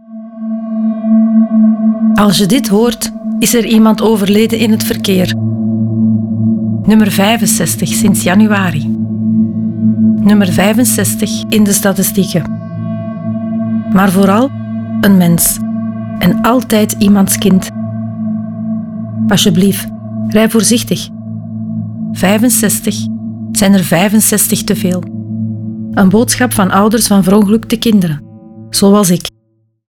Ouders van Verongelukte Kinderen maken een radiospot voor elk dodelijk verkeersslachtoffer.
De spots werden geproduced door Raygun met de medewerking van échte ouders van verongelukte kinderen, geen acteurs.